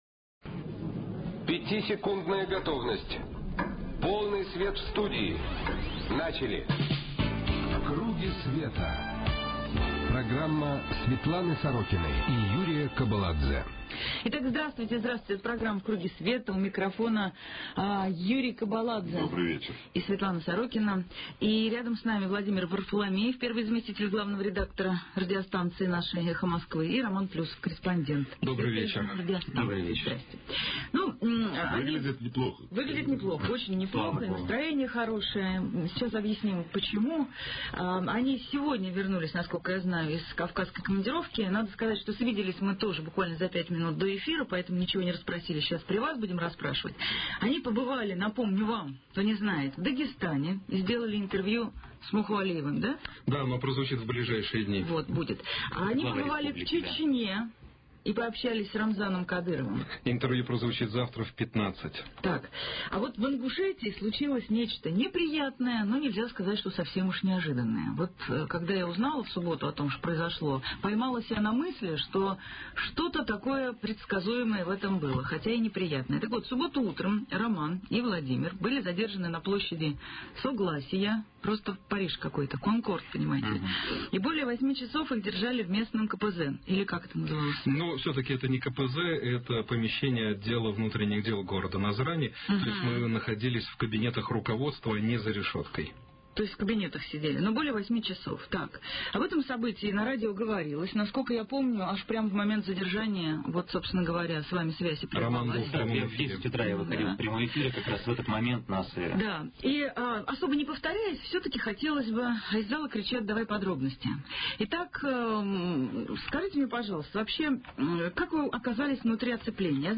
Светлана Сорокина: передачи, интервью, публикации